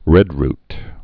(rĕdrt, -rt)